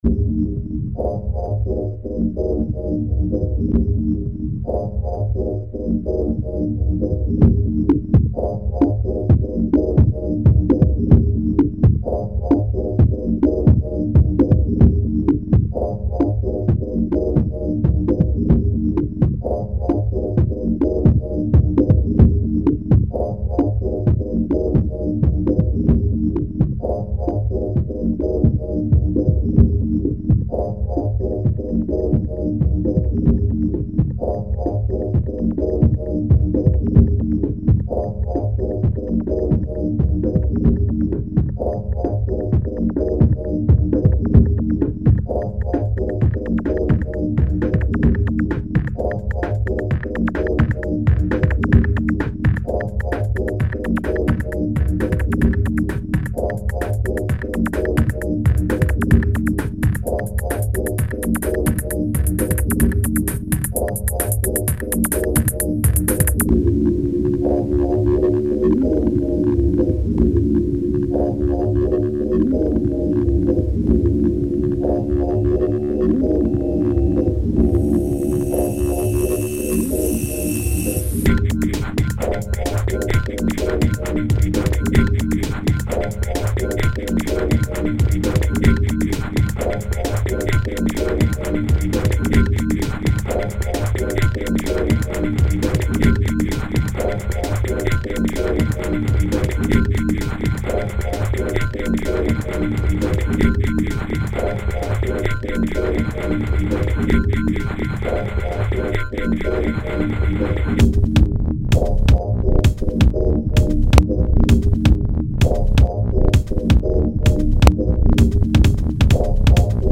Žánr: Electro/Dance